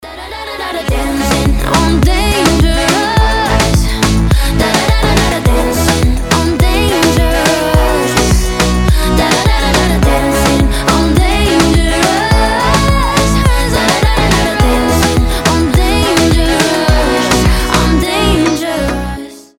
• Качество: 320, Stereo
громкие
зажигательные
женский голос
Midtempo
Заводной танцевальный рингтон